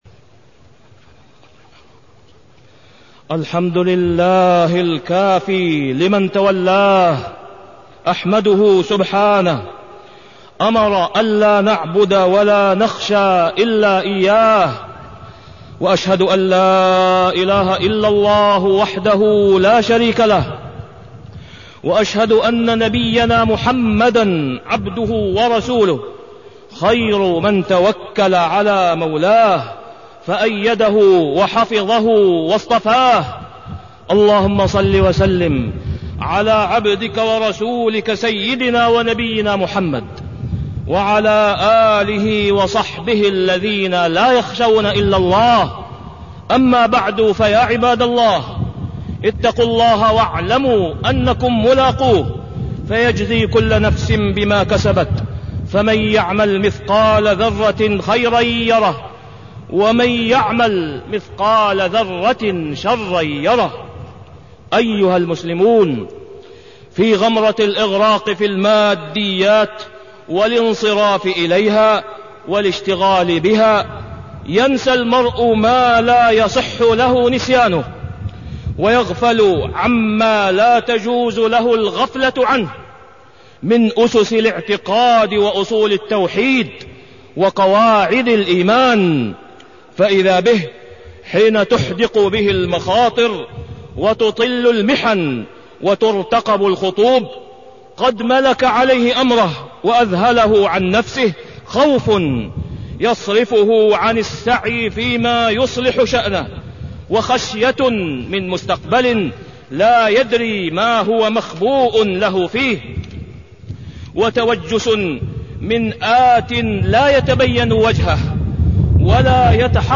تاريخ النشر ٥ شعبان ١٤٢٣ هـ المكان: المسجد الحرام الشيخ: فضيلة الشيخ د. أسامة بن عبدالله خياط فضيلة الشيخ د. أسامة بن عبدالله خياط أثر التوحيد في النفوس